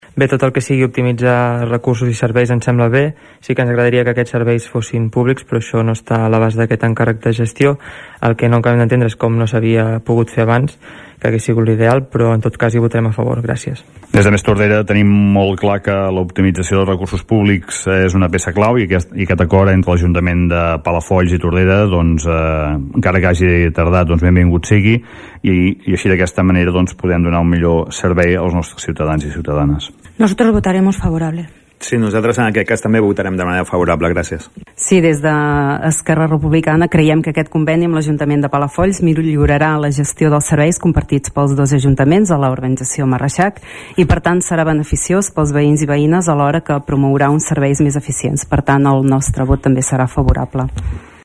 Els grups municipals van celebrar l’aprovació d’aquest conveni que permet optimitzar recursos públics. Escoltem Oriol Serra (CUP), Miriam Rocabruna (Ciutadans), Xavier Pla (+Tordera), Salvador Giralt (ECP) i Marta Paset (ERC).